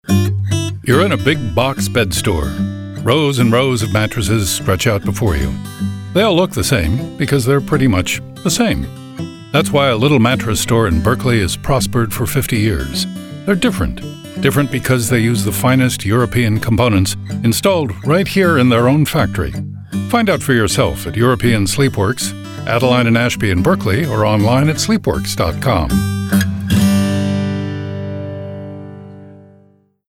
• Campaign utilizes key sonic identifiers including the unique and recognizable voice, paired with a consistent music style for its radio commercials that have led to lifts in brand awareness, traffic and sales.